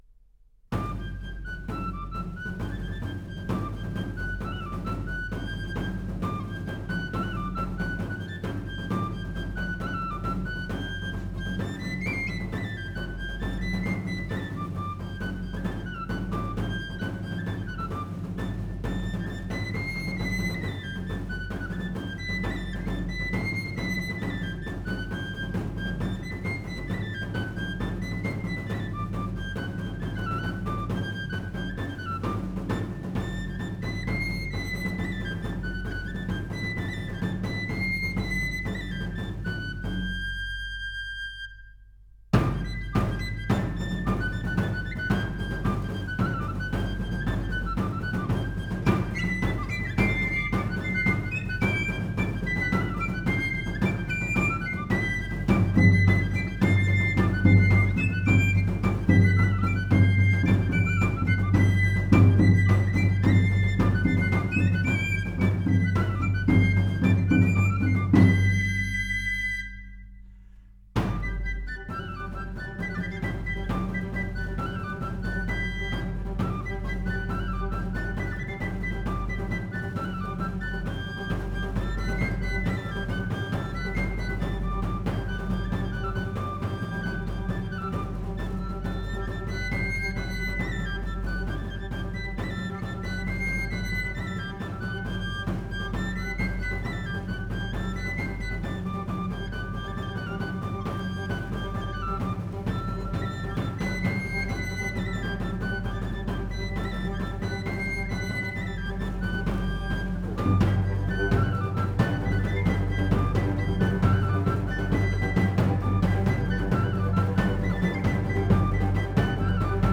Afinat en Re.
El ritme que fa és normalment simple, colpejant amb una petita baqueta i acompanyant sempre el galoubet.
Galobet i tamborin (Provença)
09-galobet-i-tamborin-provenc3a7a.m4a